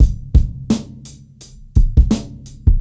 Breakbeat Fave 3 85bpm.wav